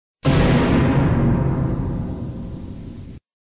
goanimate-sound-effect-scary.mp3